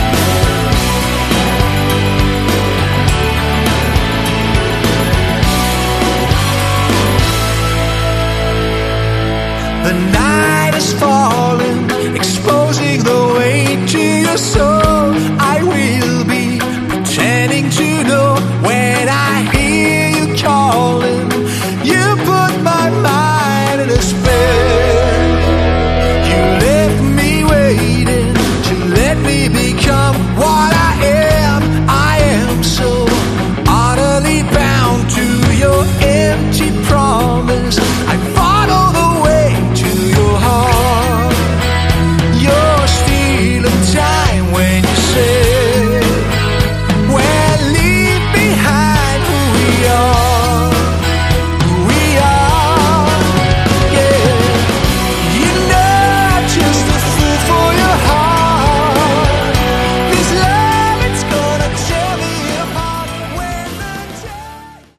Category: Melodic Hard Rock / Metal